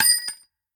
nut_impact_03.ogg